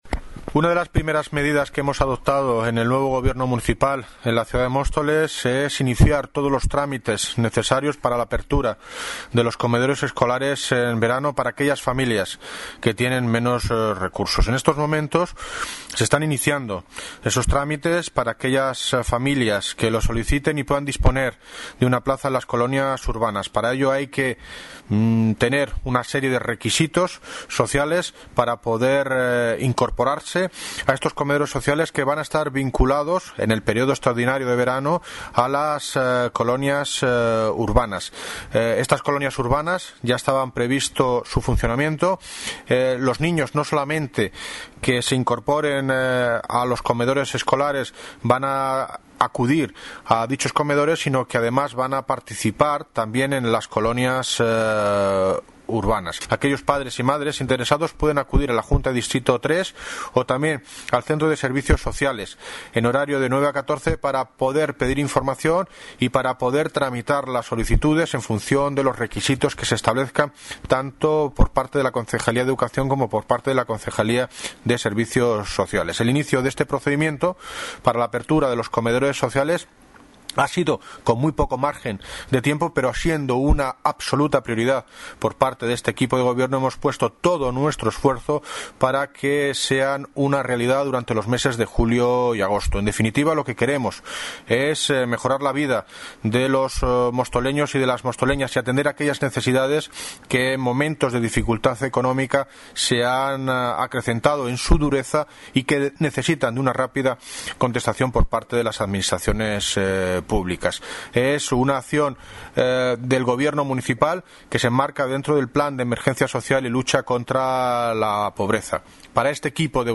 Audio - David Lucas (Alcalde de Móstoles) anuncia apertura comedores escolares en verano en Mostoles